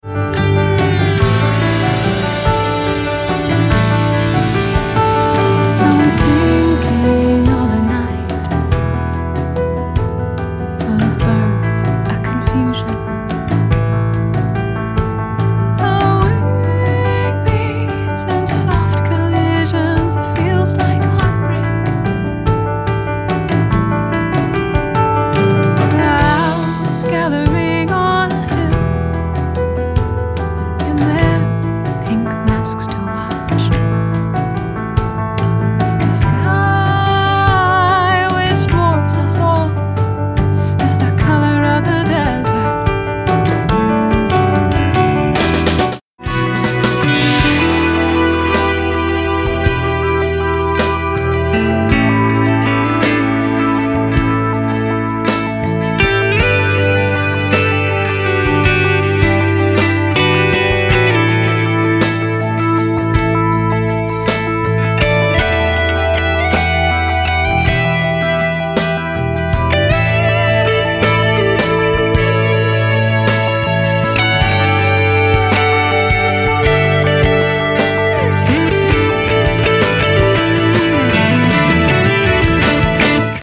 guitar playing fragments of the vocal melody
a "sample and hold" synth patch replacing the piano